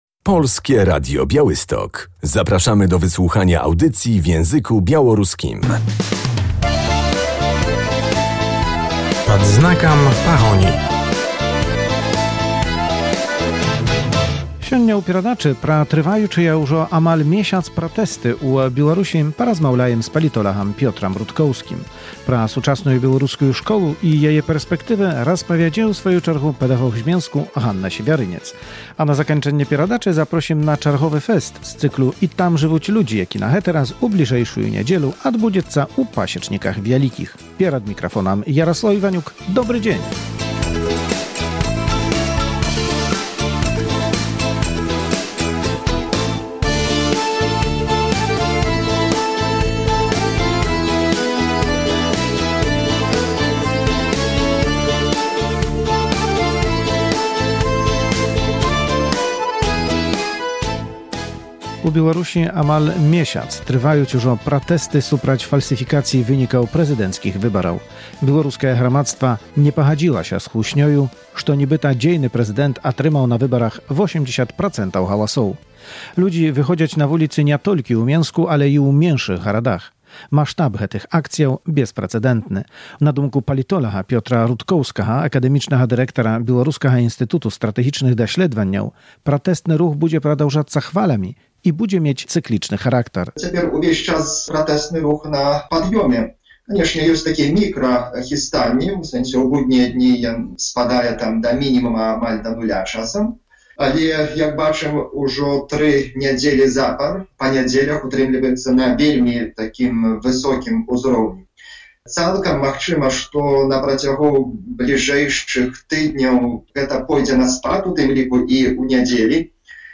Tego procesu nie da się zatrzymać – politolog o sytuacji na Białorusi 04.09.2020